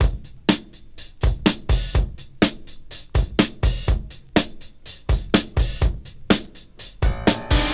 • 124 Bpm Breakbeat Sample F# Key.wav
Free drum beat - kick tuned to the F# note. Loudest frequency: 1197Hz
124-bpm-breakbeat-sample-f-sharp-key-6ef.wav